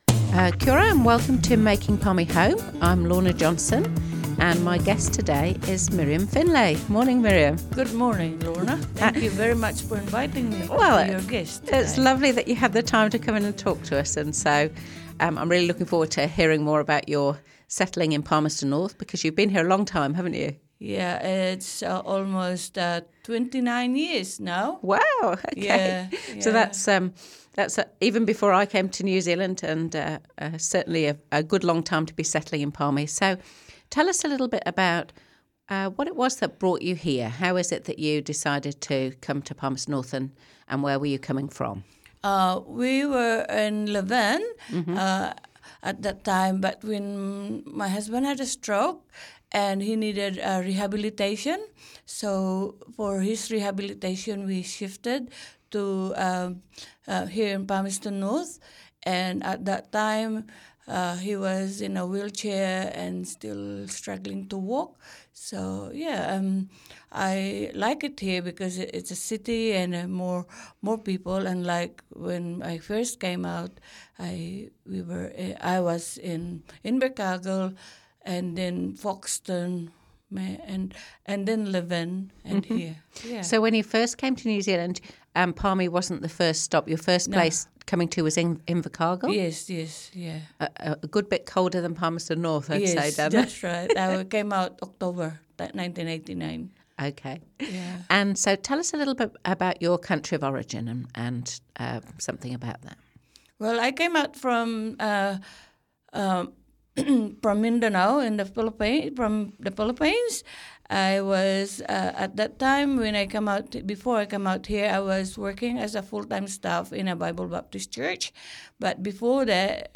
Palmerston North City Councillor Lorna Johnson hosts "Making Palmy Home", a Manawatū People's Radio series interviewing migrants about their journeys to Palmerston North.